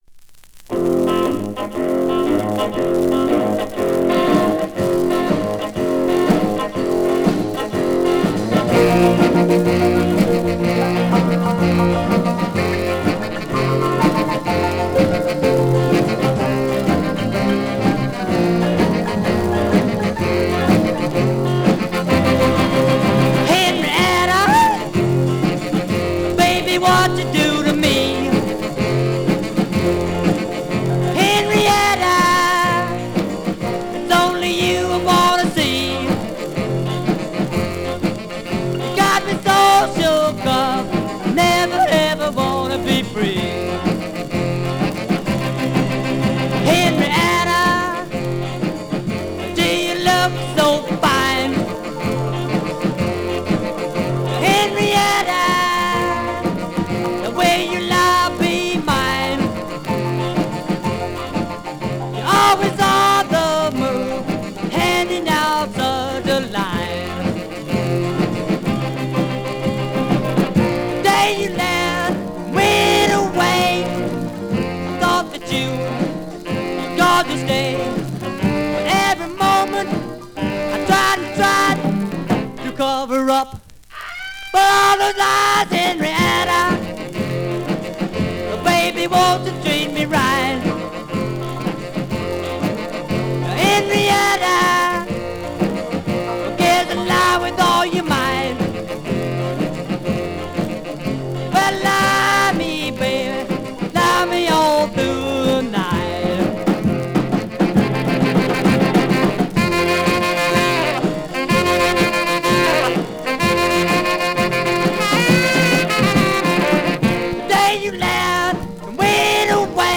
hillbilly bop